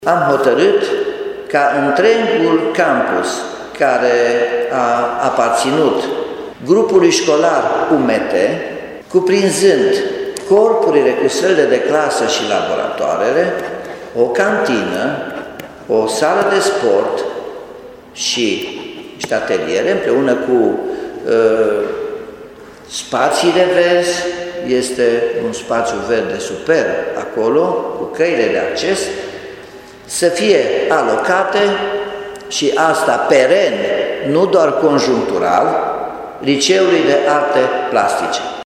Liceul de Arte Plastice din Timișoara se va muta în spațiile fostului campus al Grupului Școlar UMT.  Primarul Nicolae Robu a declarat că elevii de la Liceul de Arte vor avea condiții foarte bune de studiu.